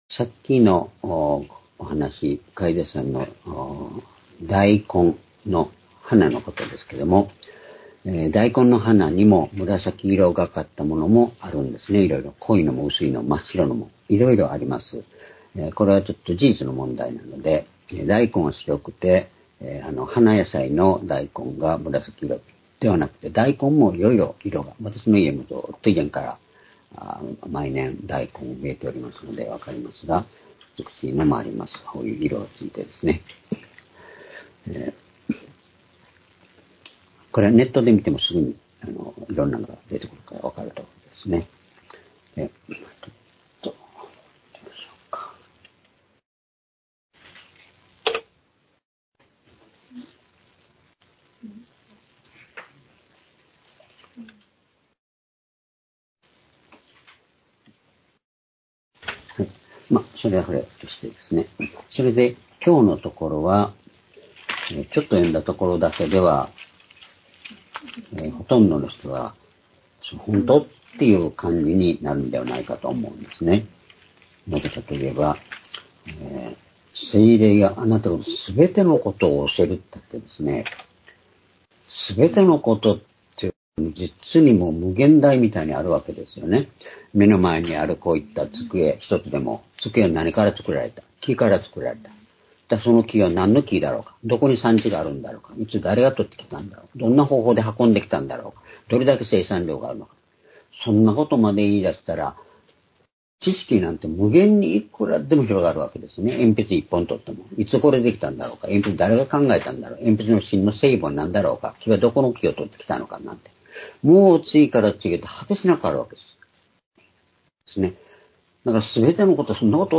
主日礼拝日時 2024年4月14日(主日) 聖書講話箇所 「聖霊がすべてを教える」 ヨハネ14章23～26節 ※視聴できない場合は をクリックしてください。